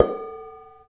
PRC BOWL H0C.wav